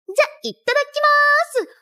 🔻Marin Kitagawa Voice🔻